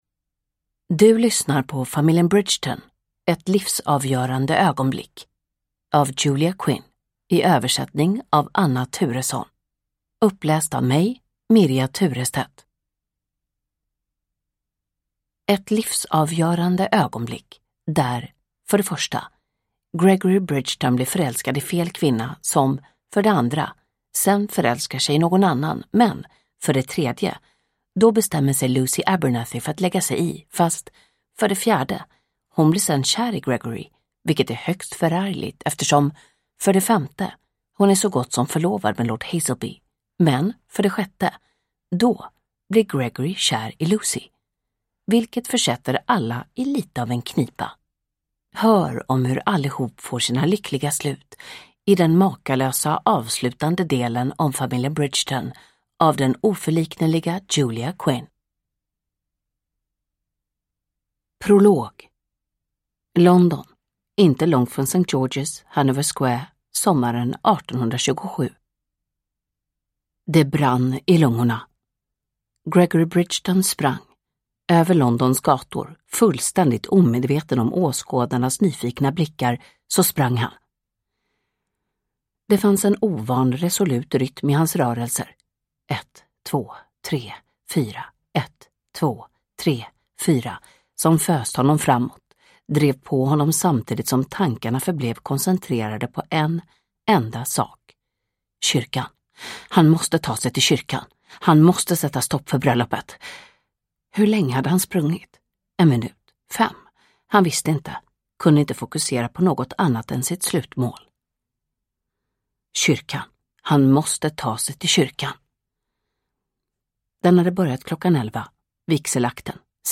Inferno – Ljudbok – Laddas ner
Uppläsare: Jonas Malmsjö